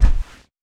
Tackle Low.wav